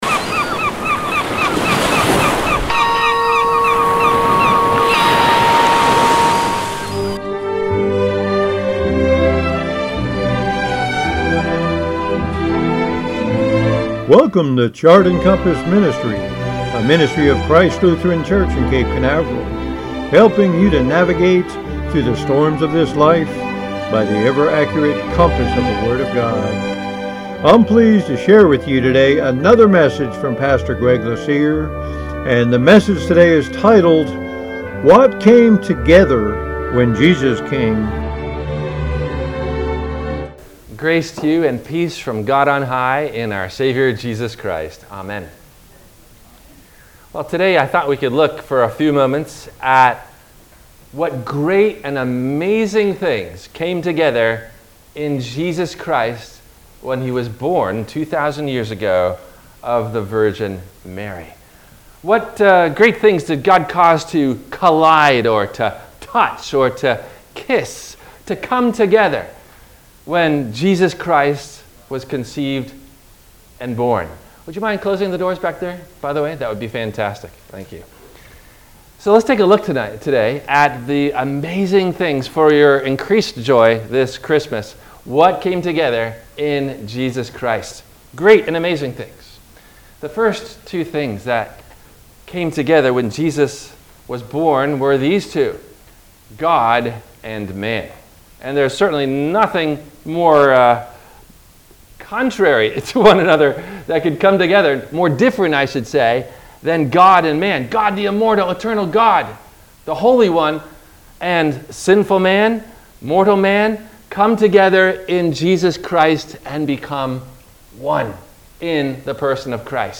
What Came Together When Jesus Came? – WMIE Radio Sermon – January 09 2023 - Christ Lutheran Cape Canaveral